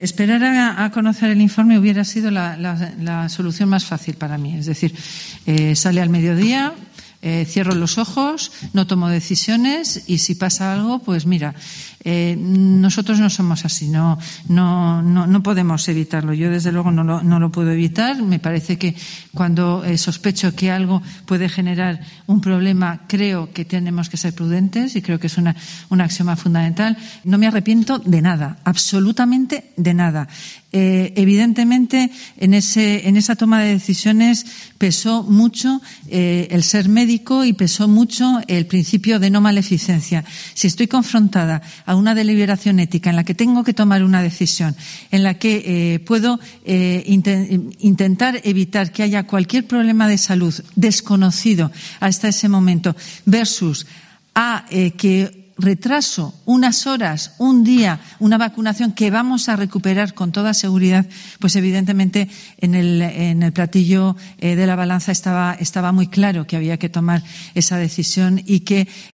“Cuando me toque, pondré mi brazo”, ha relatado en la rueda de prensa posterior a la reunión del Consejo de Gobierno.